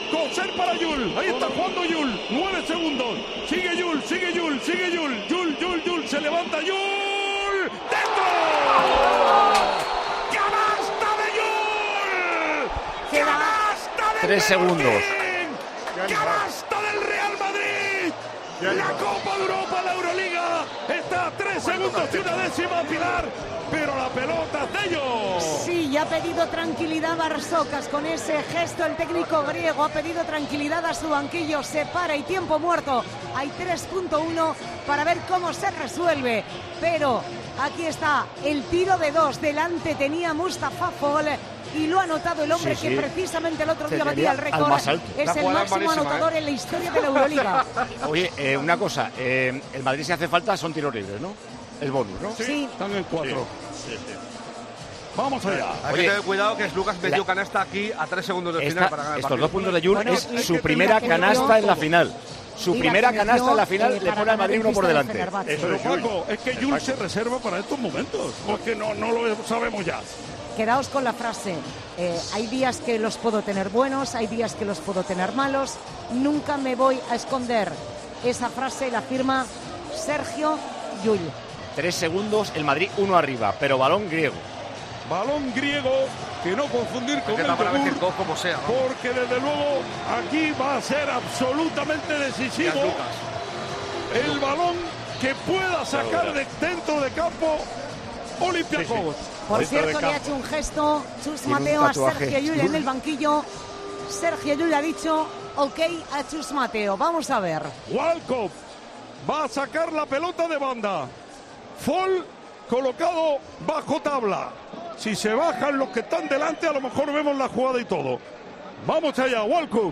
Así narramos en Tiempo de Juego la canasta de Llull y la victoria del Real Madrid en la Euroliga